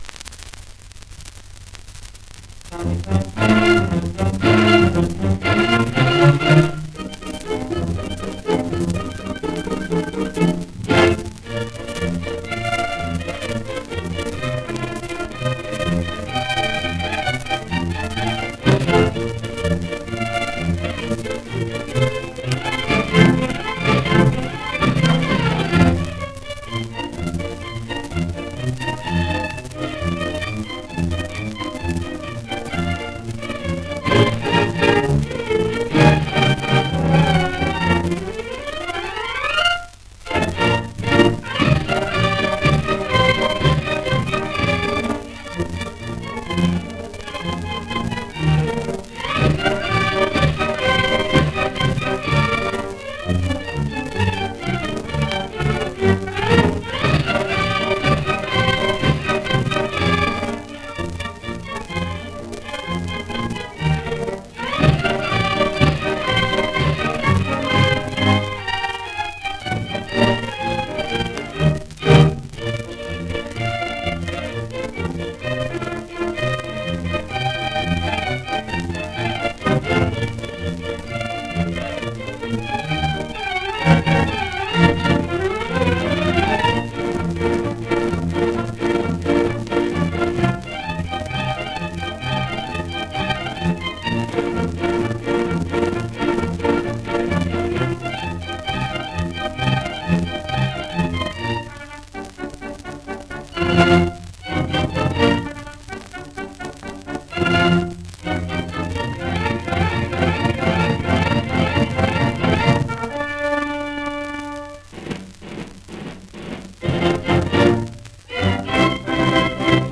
La alegría de la huerta : Pasodoble y jota